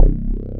bass.wav